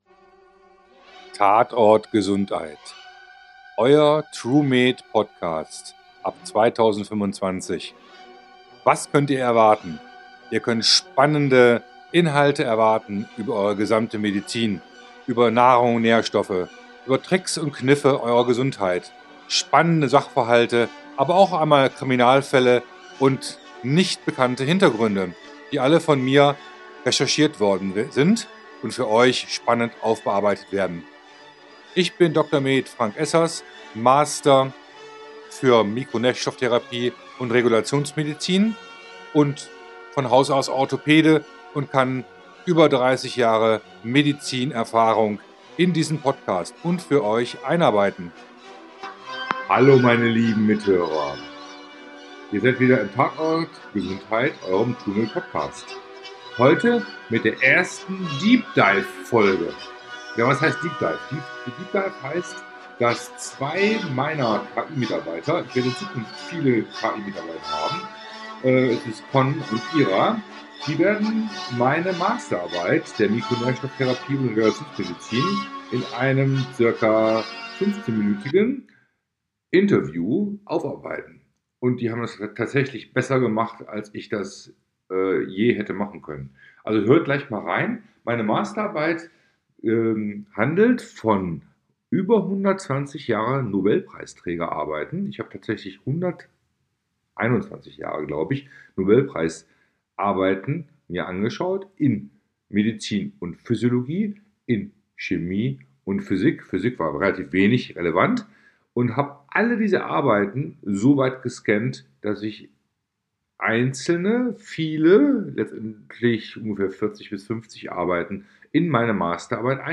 Beschreibung vor 1 Jahr Diese erste "DEEP DIVE"-Folge nutzt auch KI, künstliche Intelligenz, zur Erstellung eines spannenden Gesprächs zum Thema.